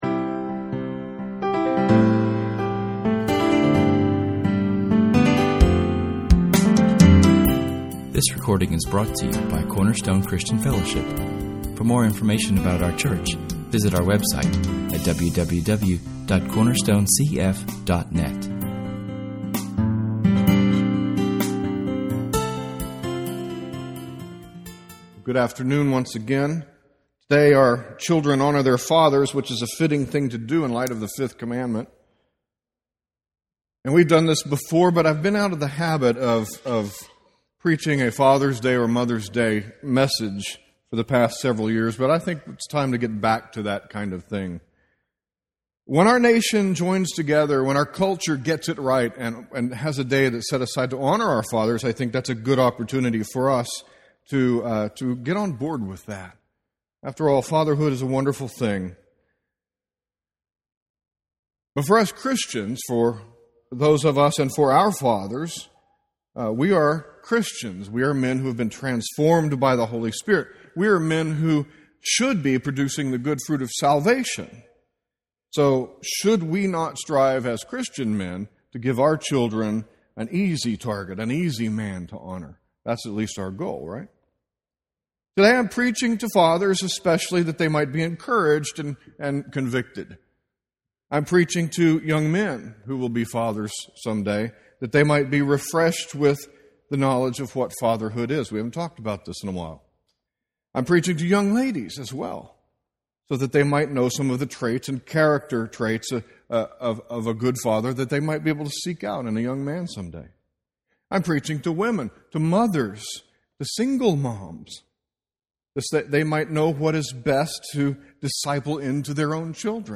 Special Occasion